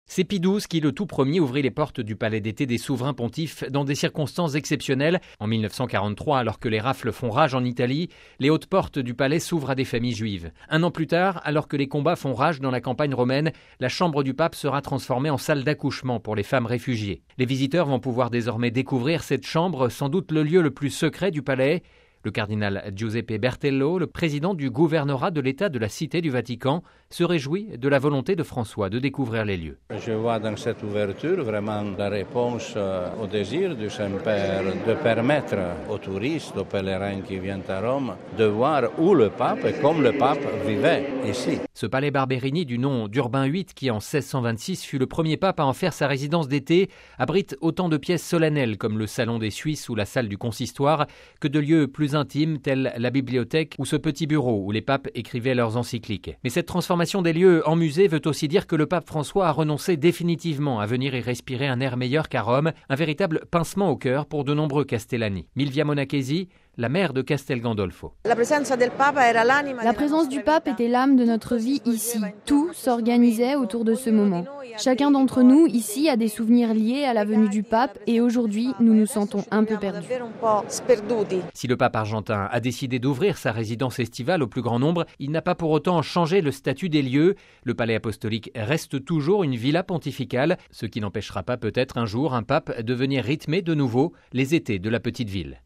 (RV) Entretien - C’est un lieu unique qui est désormais ouvert au grand public. Ce samedi 22 octobre, le palais apostolique de Castel Gandolfo, la résidence d’été des papes est désormais accessible aux visiteurs.